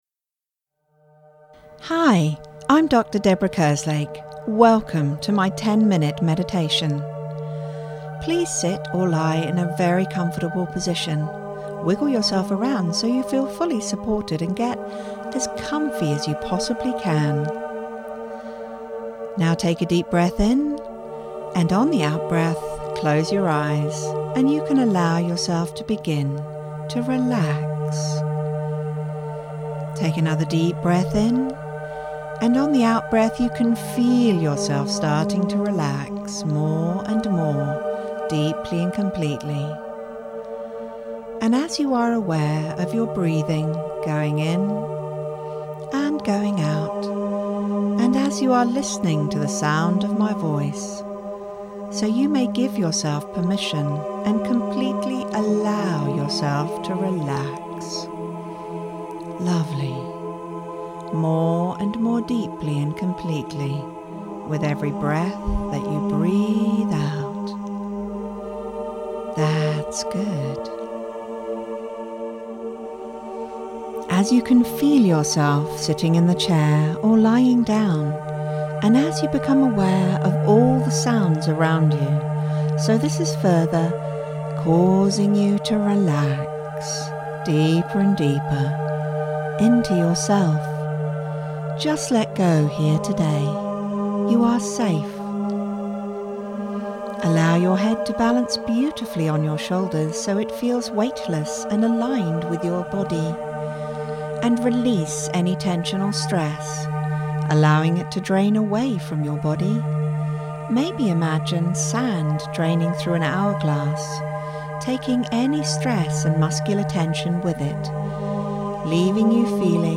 11minMeditation.mp3